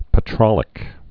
(pə-trŏlĭk)